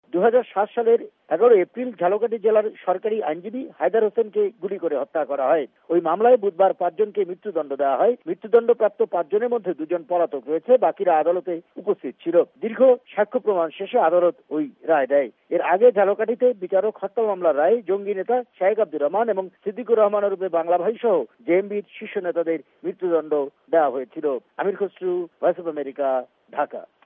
আমাদের ঢাকা সংবাদদাতাদের পাঠানো রিপোর্টের শিরোনাম ঃ বাংলাদেশে সংলাপ অনুষ্ঠানের সম্ভাবনা নাকচ। নিষিদ্ধঘোষিত জঙ্গী সংগঠনের ৫ সদস্যের মৃত্যুদন্ড।